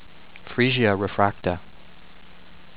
free-see-ah ray-frac-tra